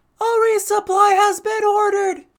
DRG-Femboy-Voice